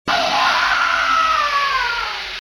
sex jumpscare Meme Sound Effect
sex jumpscare.mp3